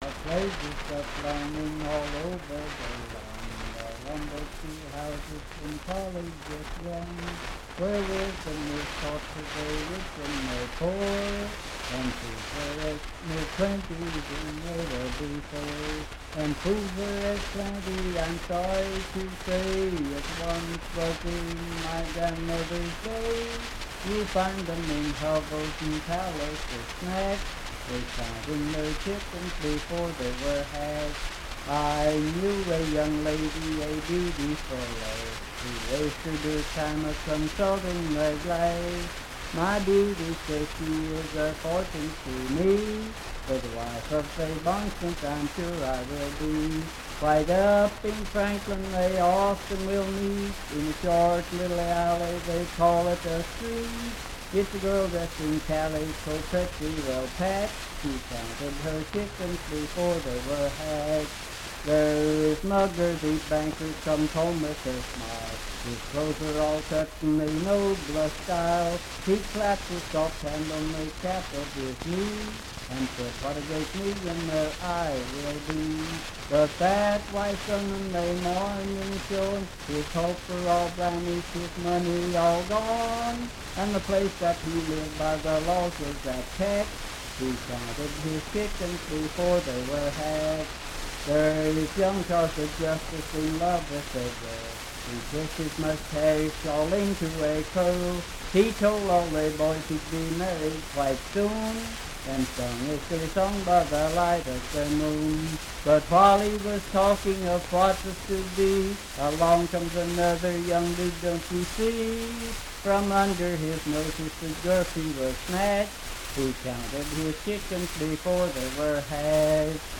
Unaccompanied vocal music
in Riverton, W.V.
Political, National, and Historical Songs, Humor and Nonsense
Voice (sung)